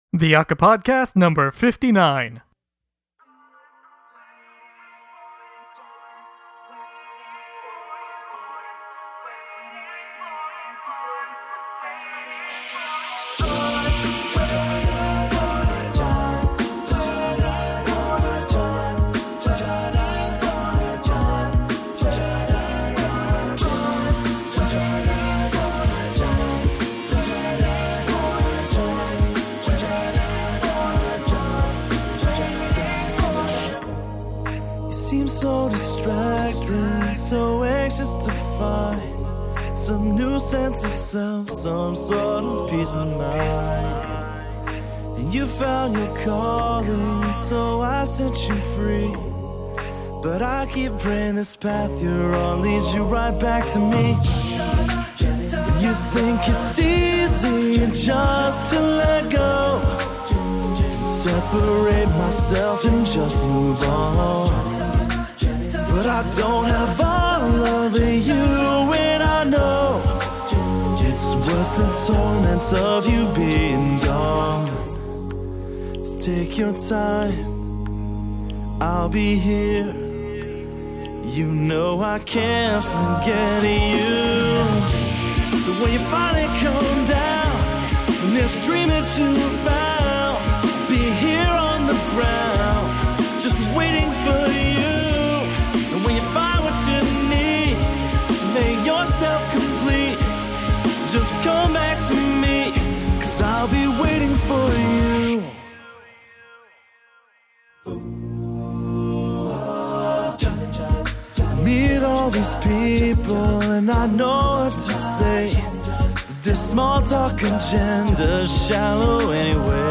Collegiate A Cappella